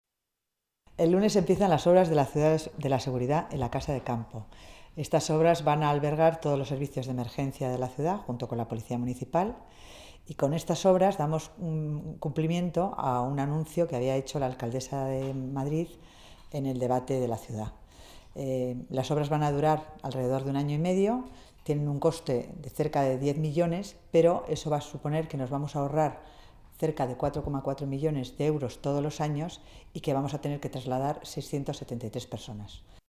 Nueva ventana:Declaraciones Concepción Dancausa